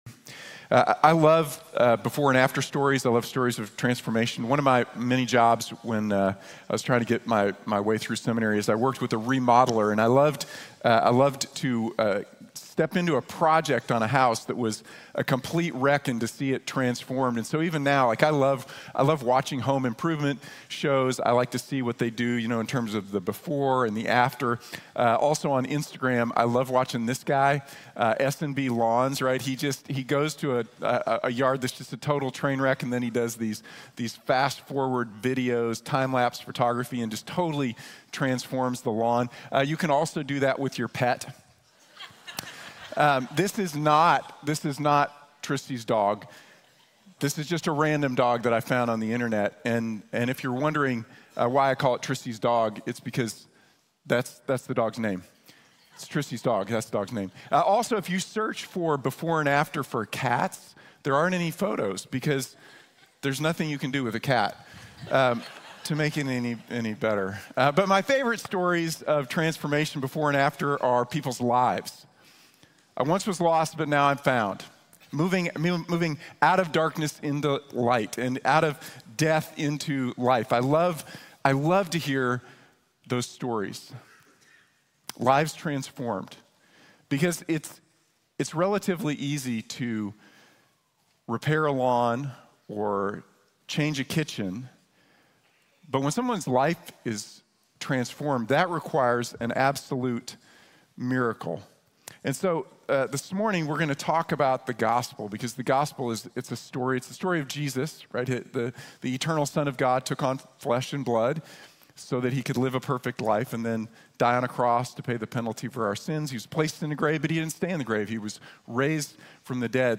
Before and After | Sermon | Grace Bible Church